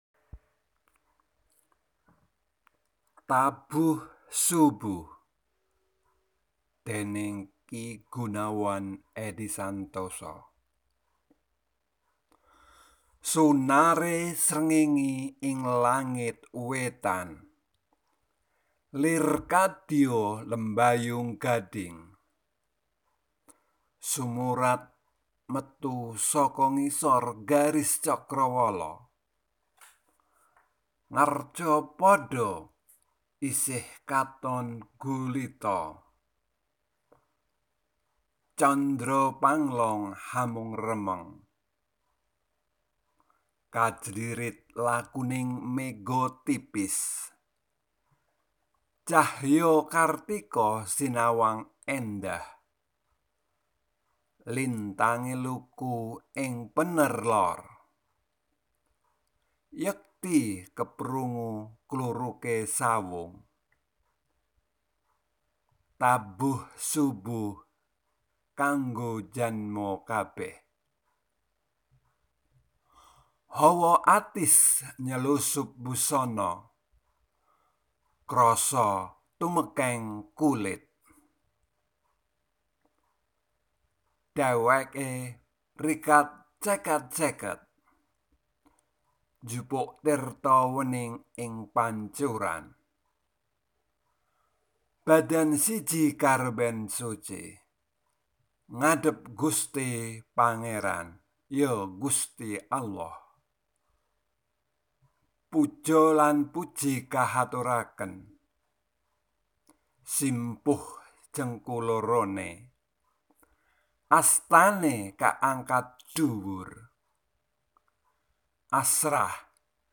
Here is a Javanese Poetry or in Javanese language it is called Geguritan.
Geguritan (Audio)